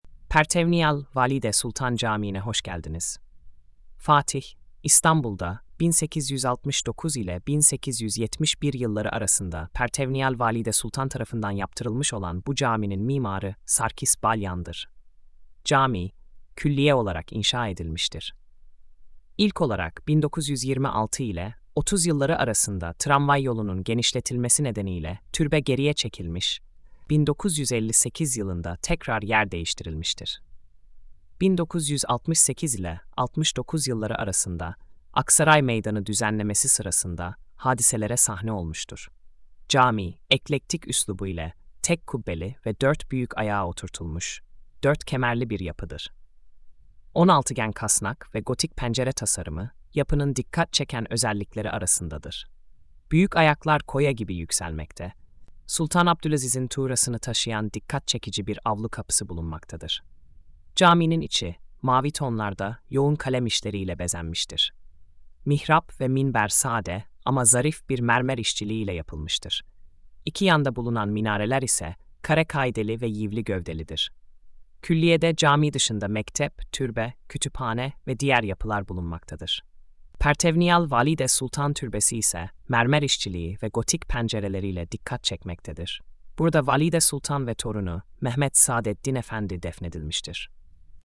Sesli Anlatım